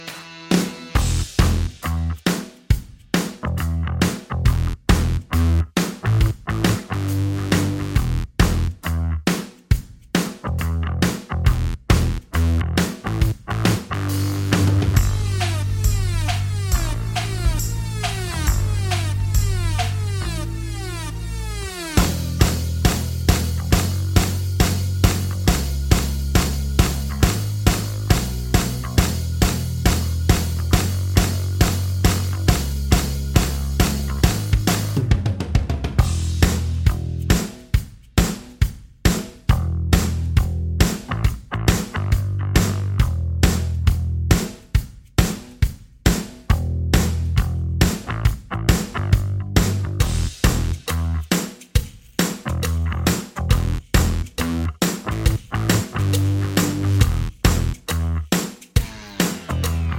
Minus Main Guitars For Guitarists 3:56 Buy £1.50